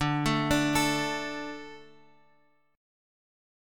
D 5th